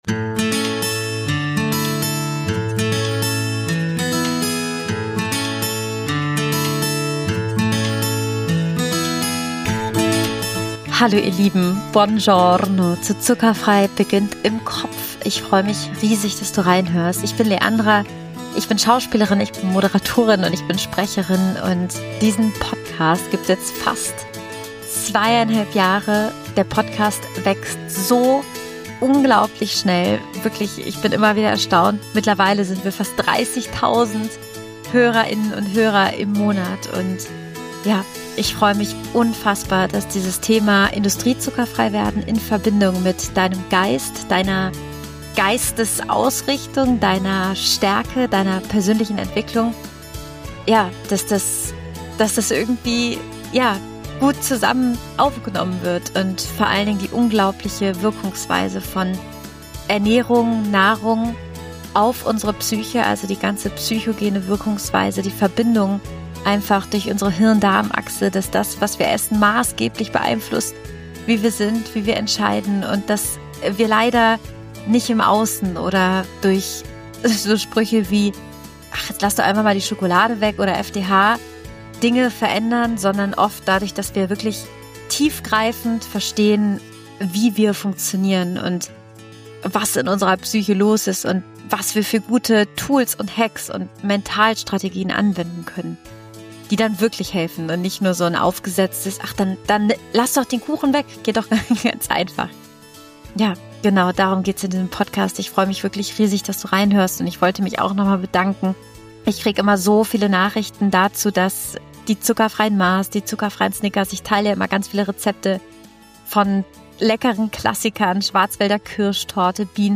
Mentale Routinen statt Zucker – Interview